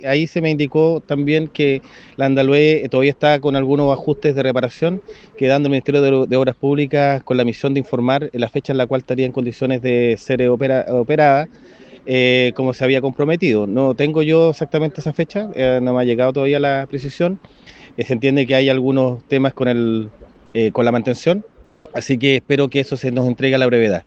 Sin embargo, eso no ocurrió, por lo tanto, este lunes La Radio le realizó la misma consulta al delegado Presidencial, Jorge Alvial, quien aseguró que en una reunión sostenida el jueves en la comuna puerto le informaron que la barcaza Andalué seguía en mantenimiento.